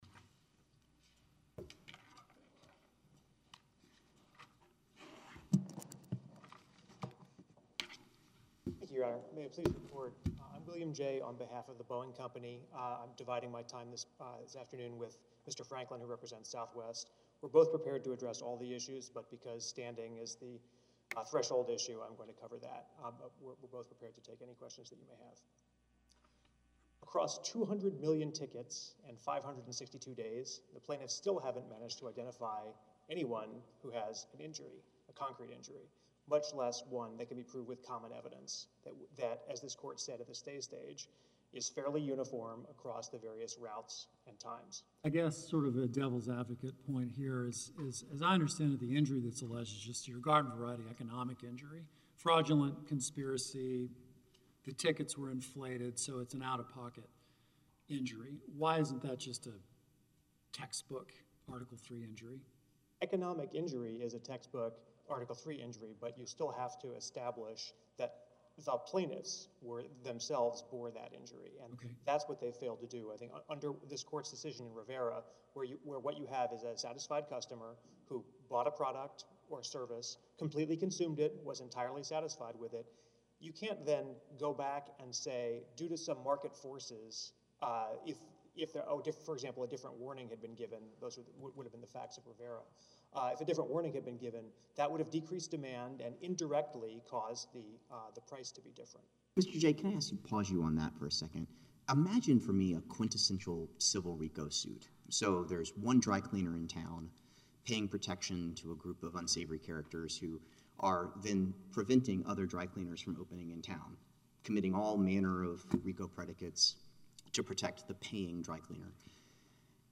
In case anyone wants to hear where Judge Oldham raised this argument at oral argument, you can listen at 31:00